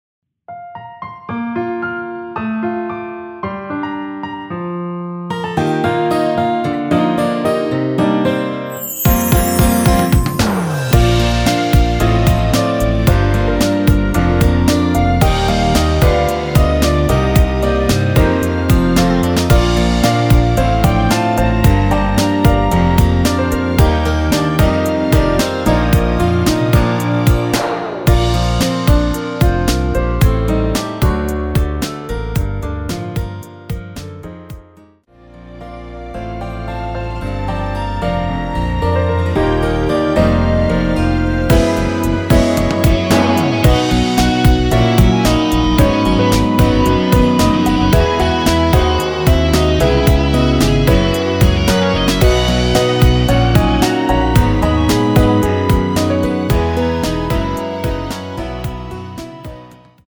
원키에서(-1)내린 멜로디 포함된 MR입니다.(미리듣기 확인)
Bb
앞부분30초, 뒷부분30초씩 편집해서 올려 드리고 있습니다.
중간에 음이 끈어지고 다시 나오는 이유는